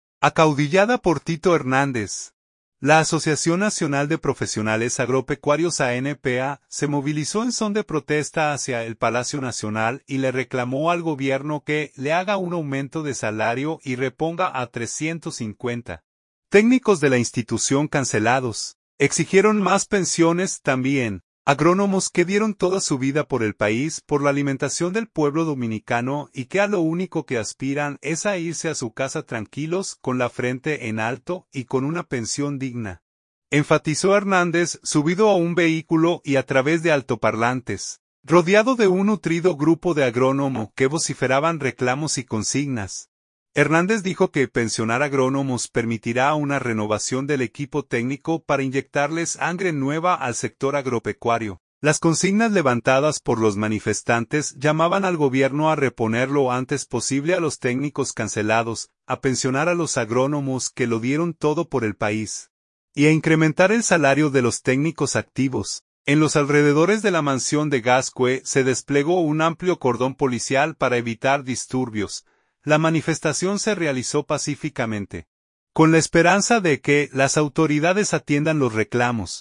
subido a un vehículo y a través de altoparlantes.
Rodeado de un nutrido grupo de agrónomo, que vociferaban reclamos y consignas